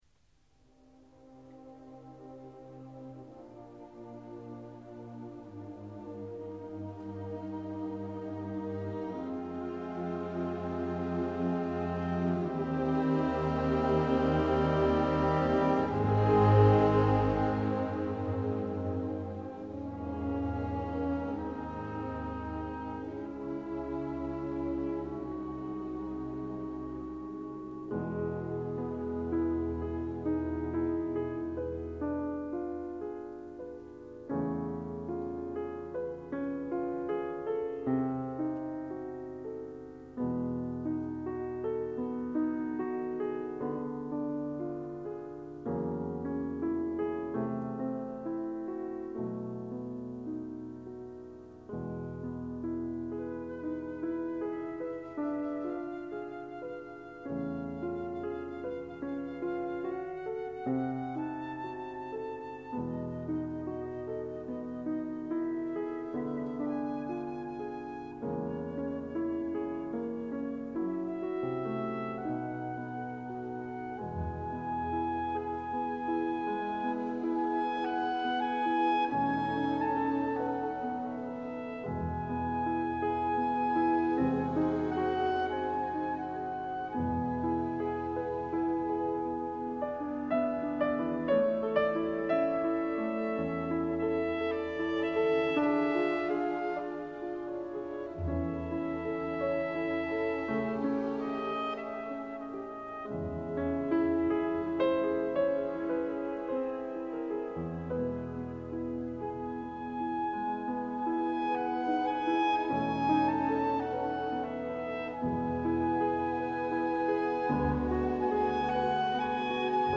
concertos pour piano
qui bénéficie de très bonnes conditions techniques
Concertos Piano